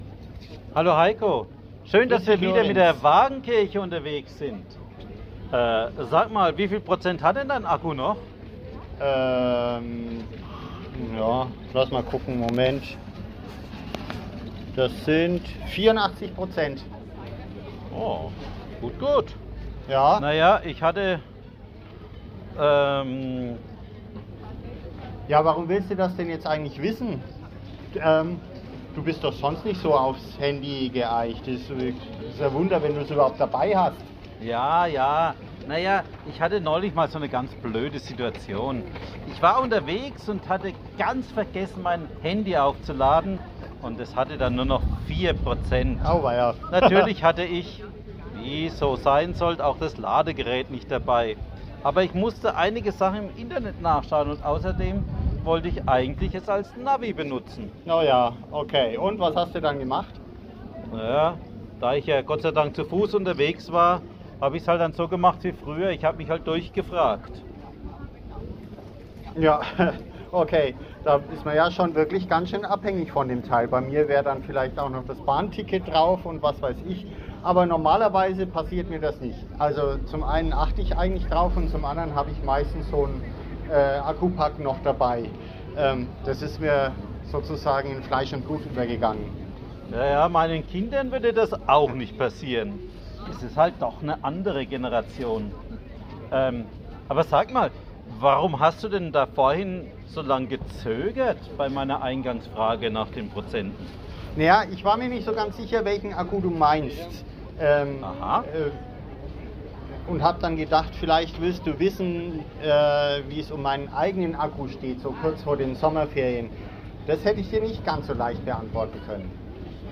Fußgängerzone. Kurze Impulse zum Nachdenken fürs Wochenende.